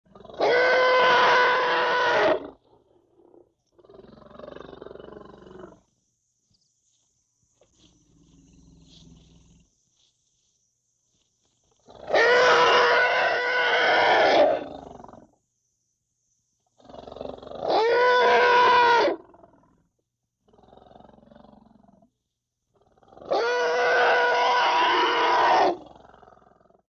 Звуки животных
Звук крошечного слоненка